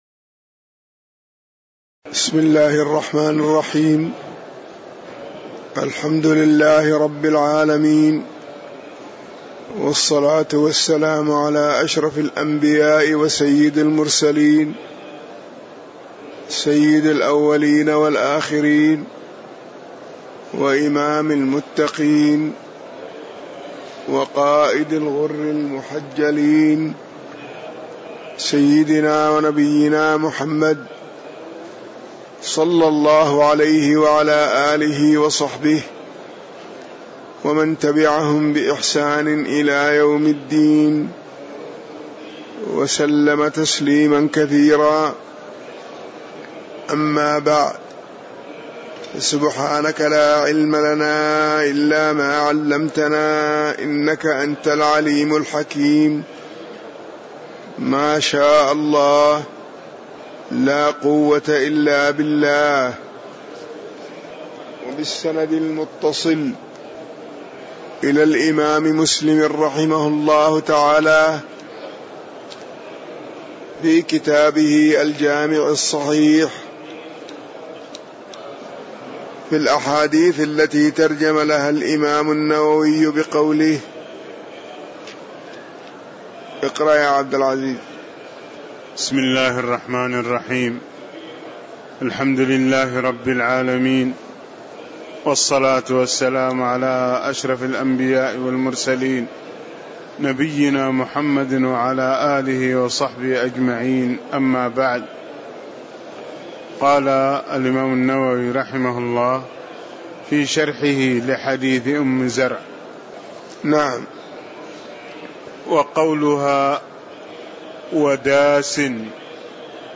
تاريخ النشر ٧ رمضان ١٤٣٧ هـ المكان: المسجد النبوي الشيخ